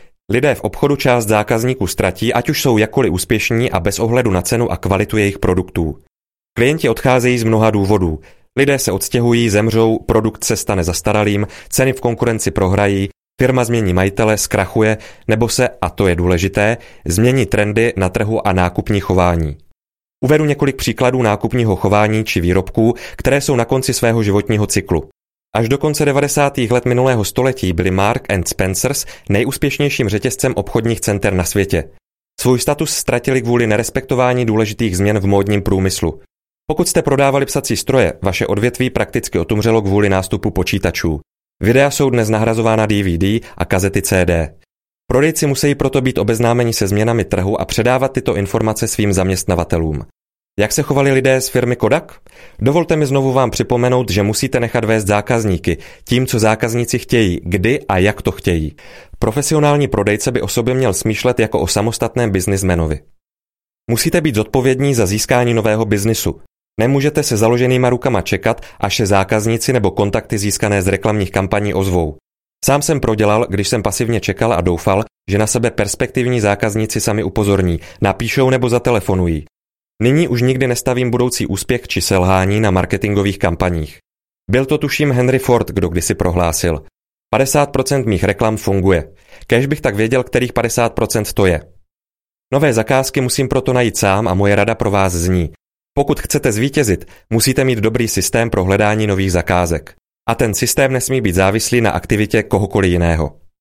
Audio knihaProdejem k vítězství
Ukázka z knihy